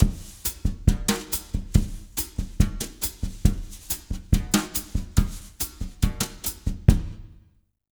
140BOSSA01-R.wav